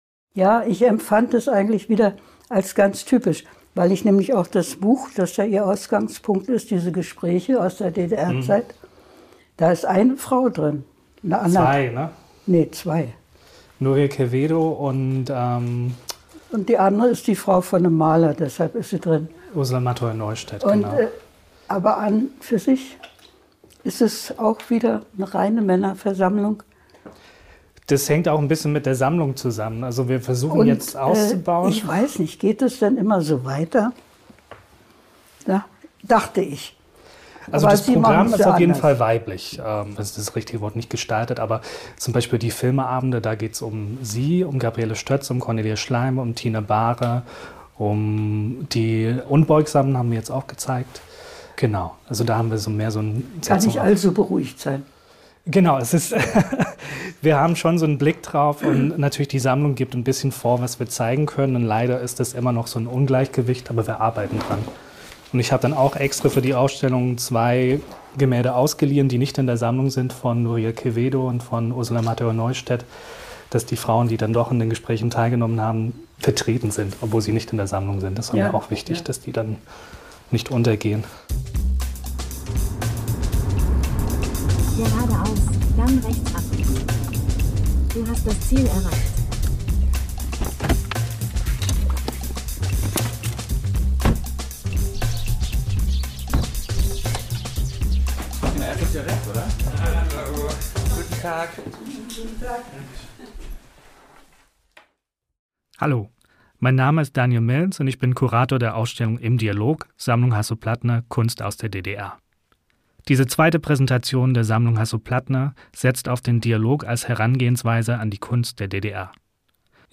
Sie spricht über Kunst als Geschenk an die Gemeinschaft und über die Freude, auch heute noch weiterzumalen. Ein Gespräch über Unabhängigkeit und über das Vertrauen in die eigene künstlerische Sprache.